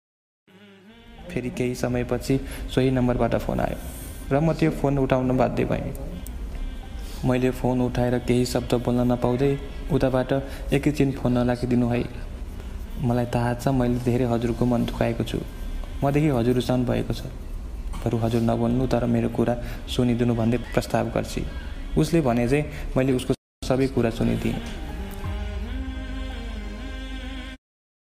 A Nepali Audio Novel